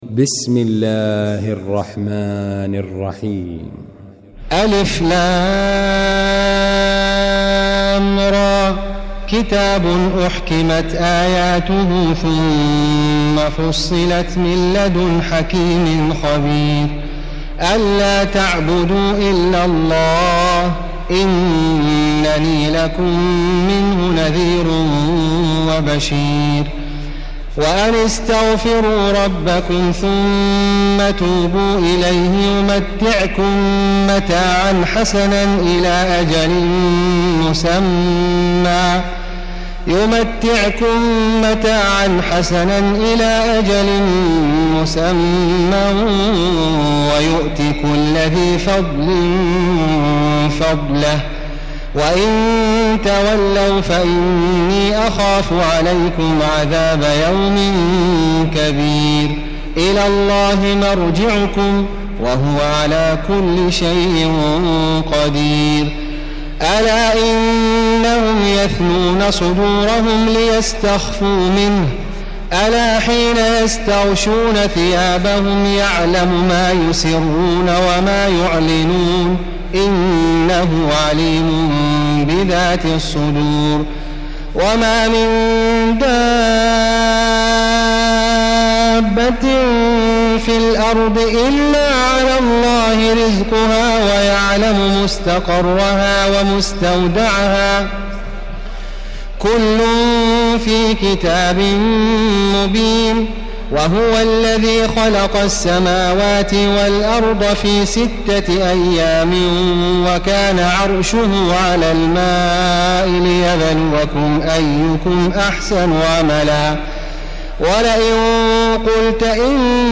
المكان: المسجد الحرام الشيخ: معالي الشيخ أ.د. بندر بليلة معالي الشيخ أ.د. بندر بليلة خالد الغامدي هود